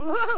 giggler.wav